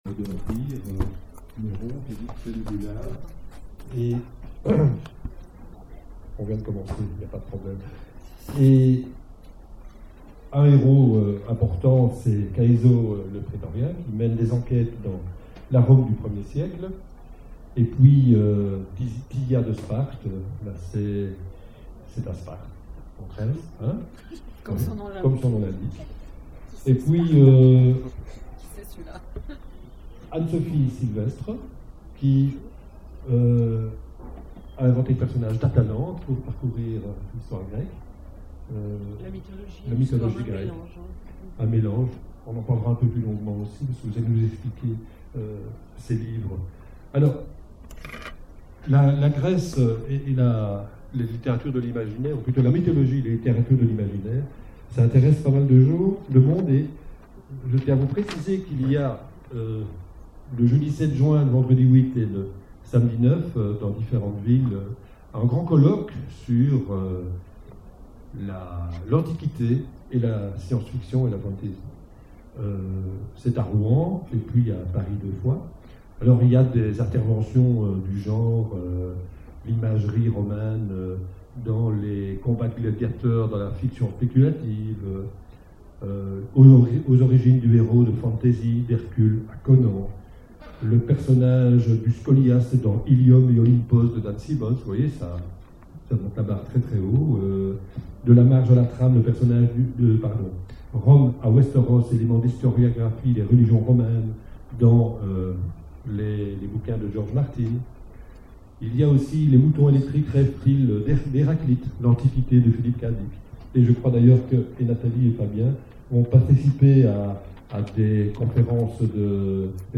Imaginales 2012 : Conférence La Grèce, Rome...